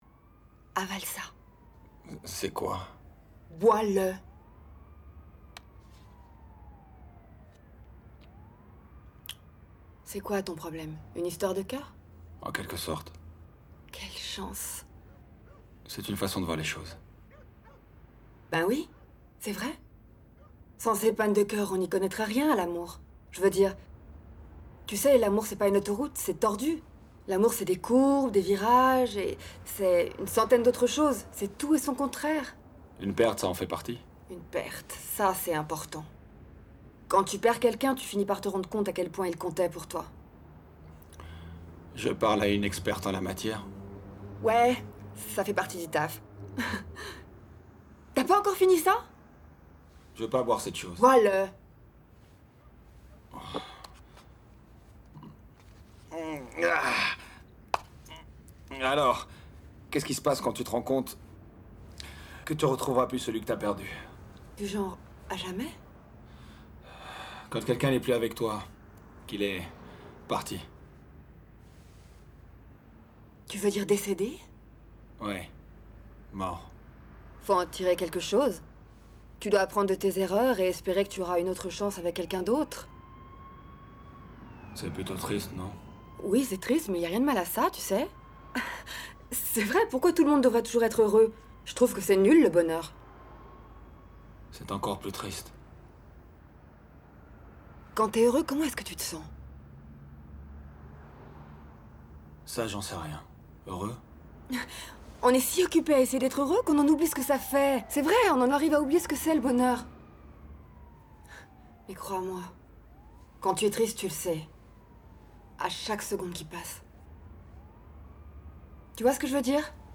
30 - 30 ans - Mezzo-soprano
doublage, voix off